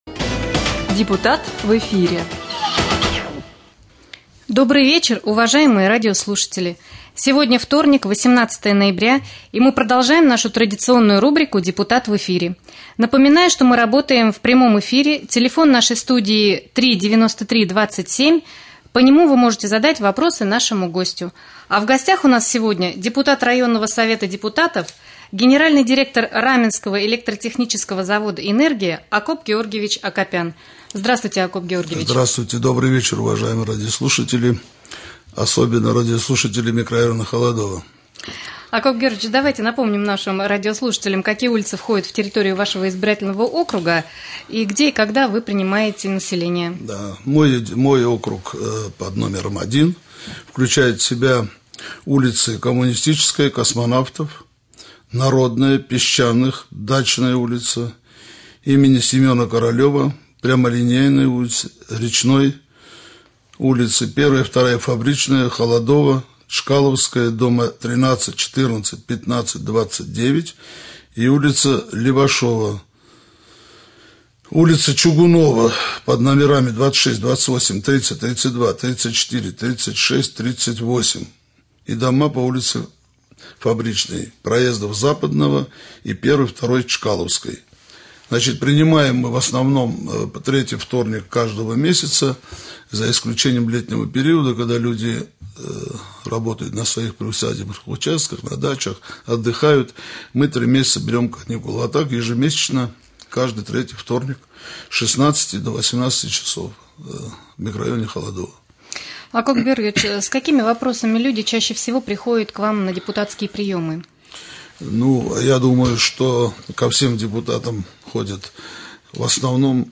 Прямой эфир с депутатом районного Совета Депутатов, генеральным директором Раменского электротехнического завода «Энергия» Акопом Георгиевичем Акопяном